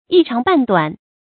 一長半短 注音： ㄧ ㄔㄤˊ ㄅㄢˋ ㄉㄨㄢˇ 讀音讀法： 意思解釋： 見「一長二短」。